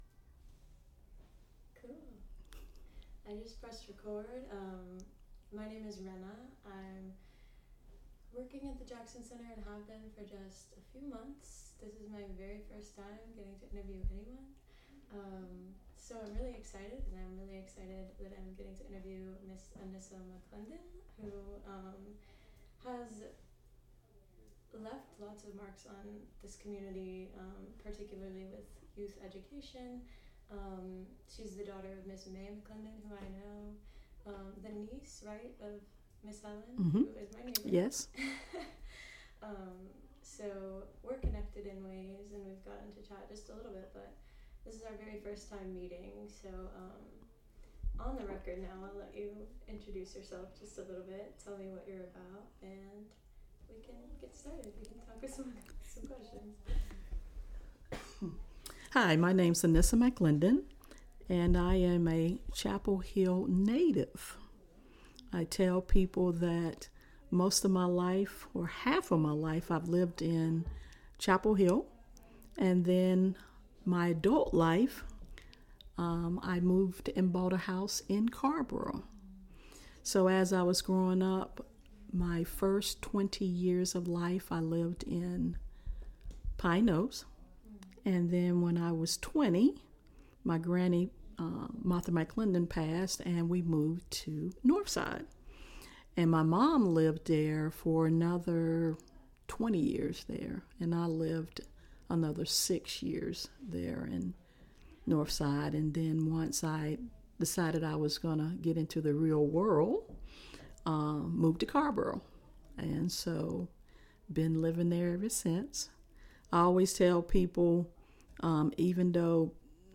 Explore the history of Black communities in Chapel Hill and Carrboro, NC. Meet our neighbors through oral history interviews, images, and more.